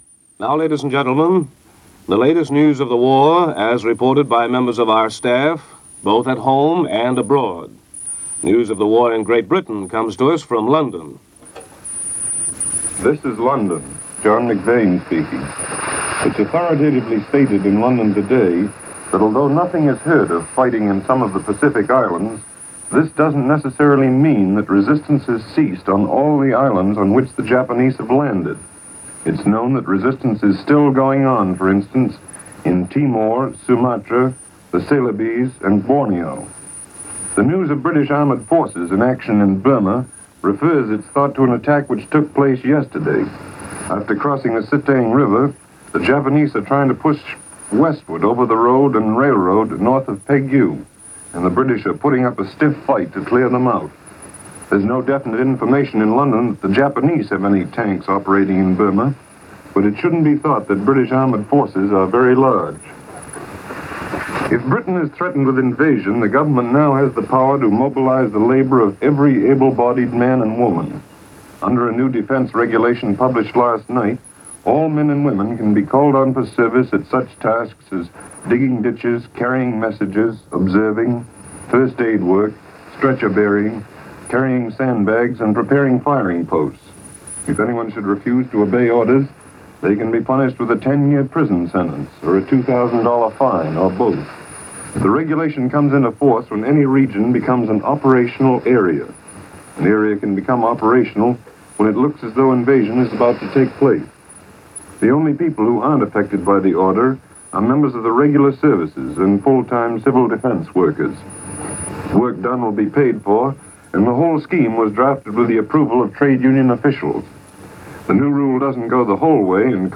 News-March-7-1942.mp3